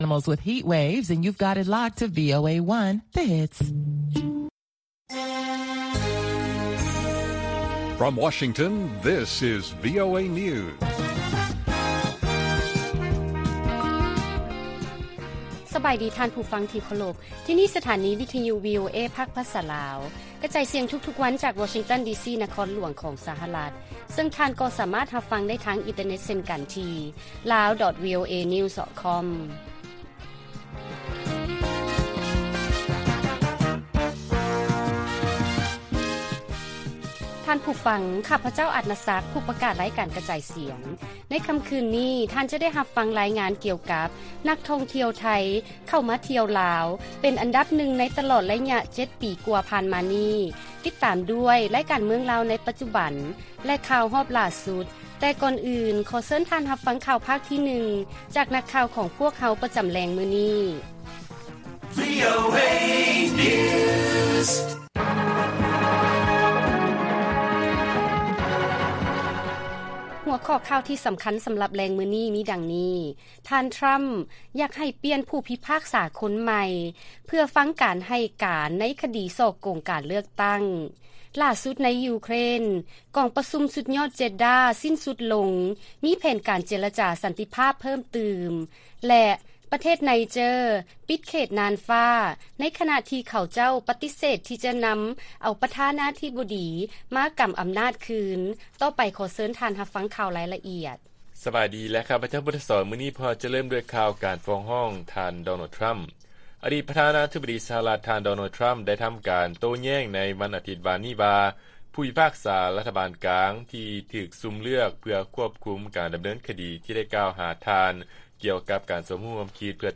ລາຍການກະຈາຍສຽງຂອງວີໂອເອ ລາວ: ທ່ານ ທຣຳ ຢາກໃຫ້ປ່ຽນຜູ້ພິພາກສາຄົນໃໝ່ ເພື່ອຟັງການໃຫ້ການໃນຄະດີສໍ້ໂກງການເລືອກຕັ້ງ